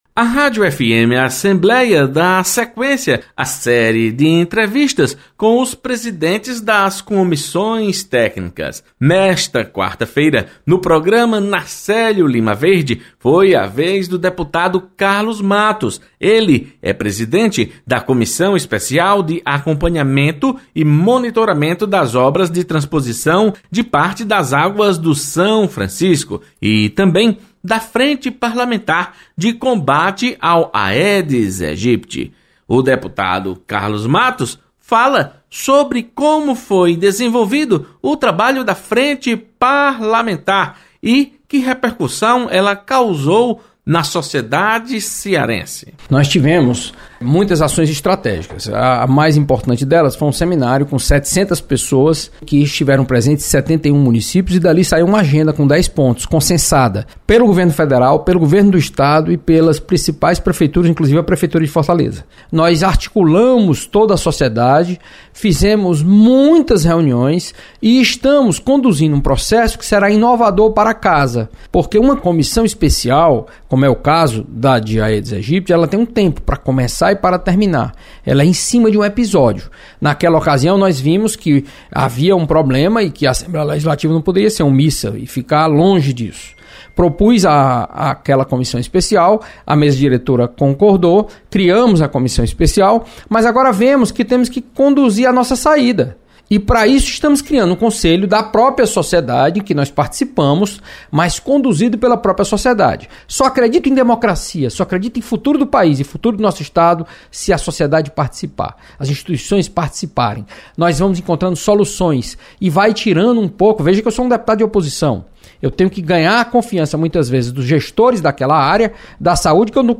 Deputado Carlos Matos apresenta balanço da Frente Parlamentar de Combate ao Aedes Aegypti e da Comissão Especial para Acompanhar e Monitorar as Obras de Transposição do Rio São Francisco. Repórter